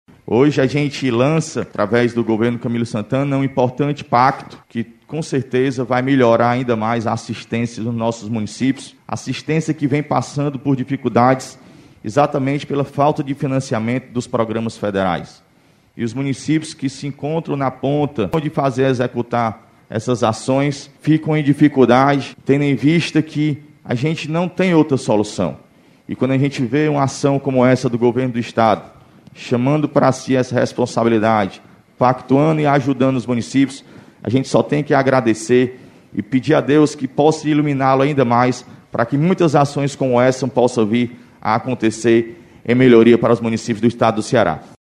A solenidade de lançamento, que aconteceu no Palácio da Abolição, em Fortaleza, serviu como um dos eventos-teste do Governo do Ceará na retomada gradual dos eventos por causa da pandemia do Coronavírus.
A ajuda anunciada com o pacto aos municípios será fundamental neste momento de dificuldade que o país atravessa, de acordo com Júnior Castro, prefeito de Chorozinho e presidente da Associação dos Municípios do Ceará (Aprece).